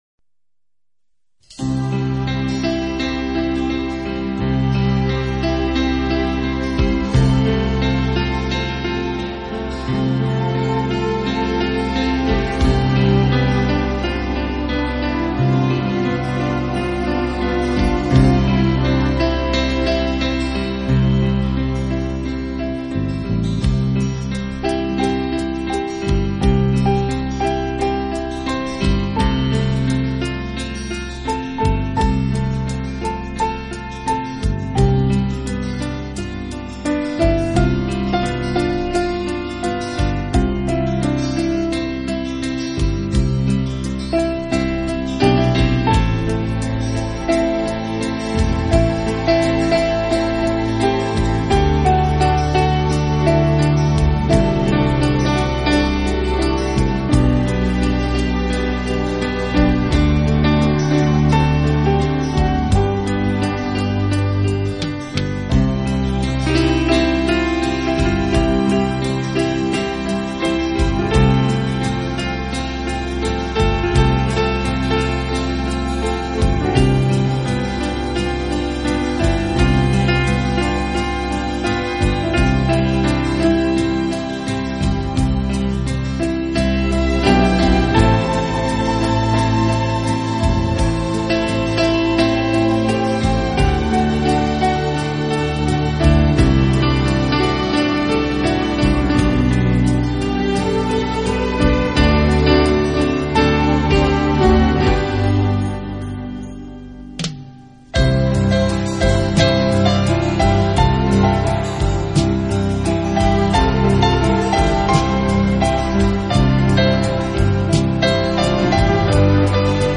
钢琴演奏版，更能烘托出复古情怀，欧美钢琴大师深具质感的演奏功力，弹指
本套CD全部钢琴演奏，